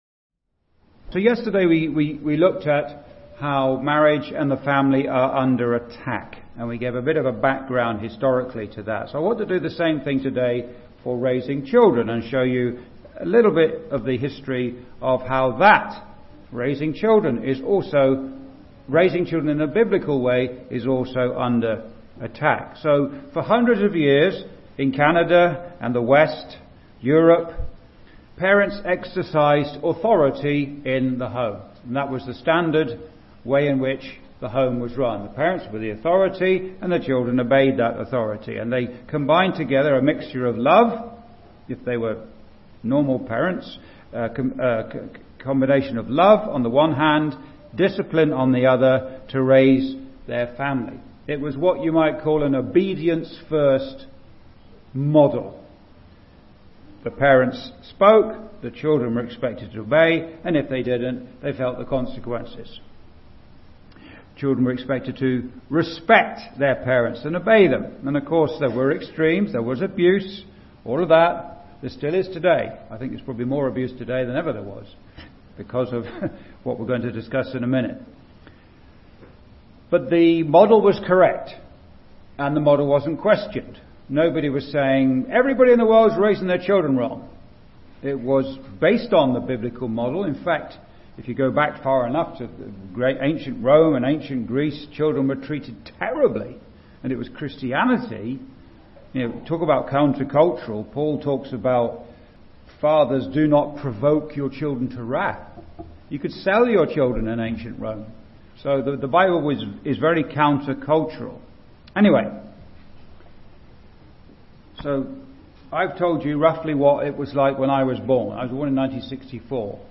(Recorded in Straffordville Gospel Hall, ON, Canada, on 7th Jan 2026) Complete series: Countercultural Christianity